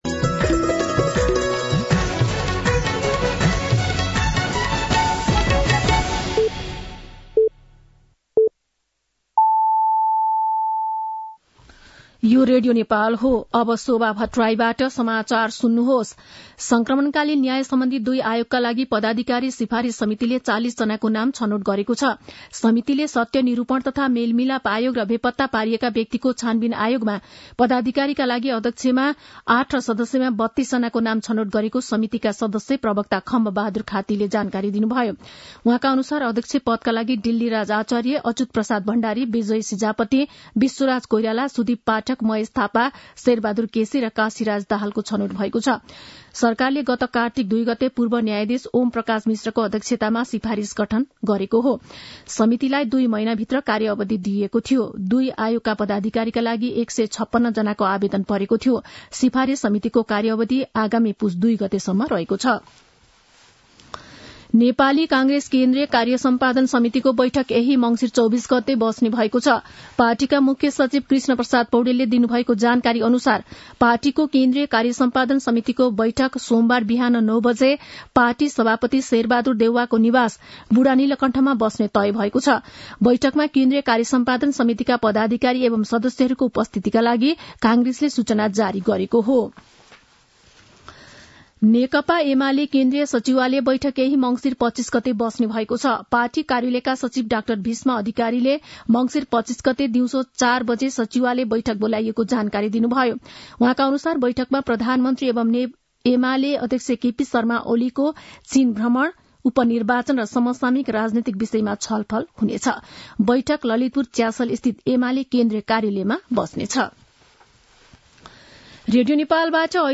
मध्यान्ह १२ बजेको नेपाली समाचार : २३ मंसिर , २०८१
12-pm-Nepali-News.mp3